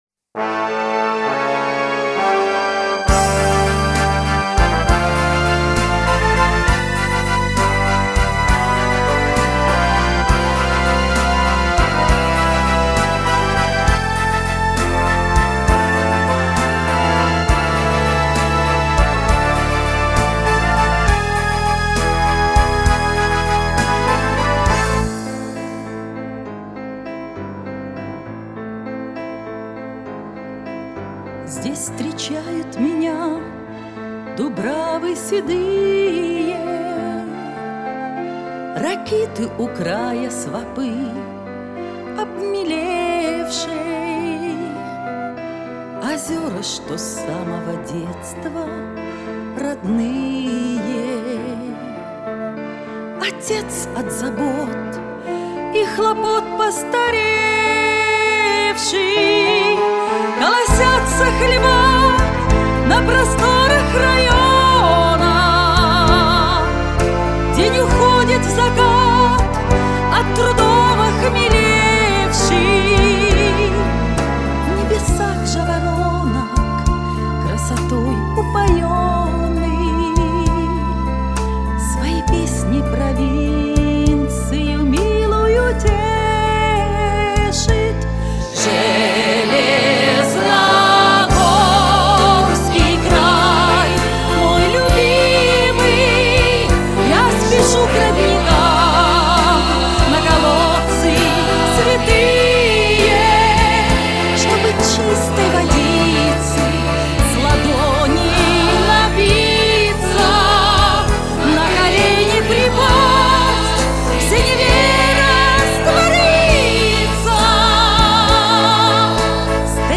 Женский вокал, силён и очень красив!